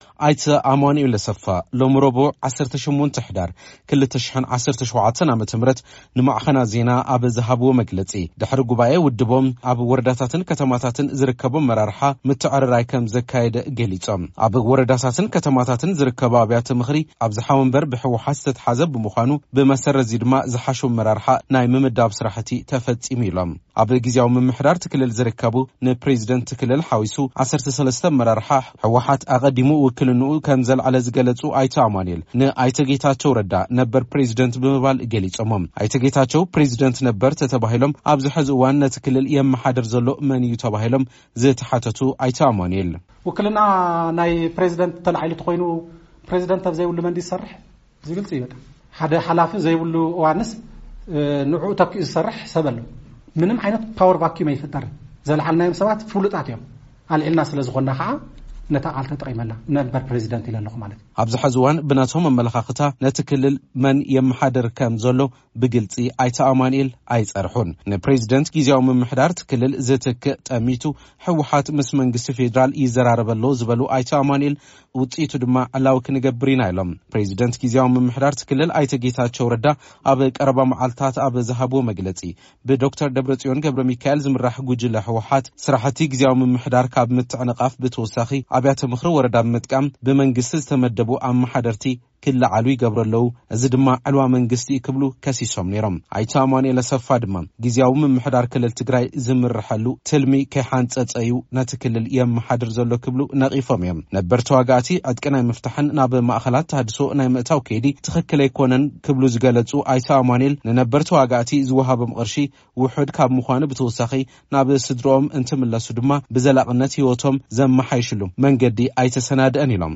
ኣብ ቀረባ እዋን ኣብ ዝተኻየደ ጉባኤ ህወሓት ምኽትል ኣቦ-መንበር ኰይኖም ዝተርመጹ ኣይተ ኣማኑኤል ኣሰፋ፡ ሎሚ ረቡዕ ምስ ማዕከናት ዜና ኣብ ዝገበሩዎ ጻንሒት፡ ግዜያዊ ምምሕዳር መንእሰያት ኣሰልጢኑ ህወሓት ንምፍራስ ይሰርሕ ኣሎ ክብሉ ከሲሶም። ነቲ ኣብ ቀረባ እዋን ዝጀመረ ናይ ምፍናውን ምጥያስ ዕጡቓት ነበር መስርሕ ዝነቐፉ ኣይተ ኣማኑኤል፡ ኣቐዲሙ ብፕረዚደንት ግዜያዊ ምምሕዳር እቲ ክልል ኣይተ ጌታቸው ረዳ ንዝተወሃበ መግለጺ’ውን ምላሽ ሂቦም ኣለዉ። ኣብዚ ጉዳይ ካብ ቢሮ ኮምኒከሽን ግዜያዊ ምምሕዳር እቲ ክልል ግብረ-መልሲ ንምስማዕ ዝግበርናዮ ጻዕሪ ኣይሰመረን።